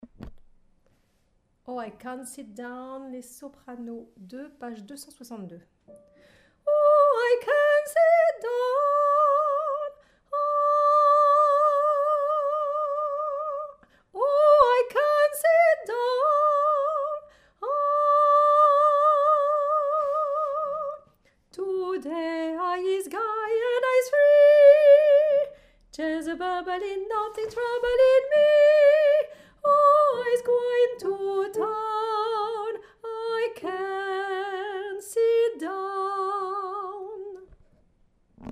Soprano2
oh-i-can-t_Soprano2.mp3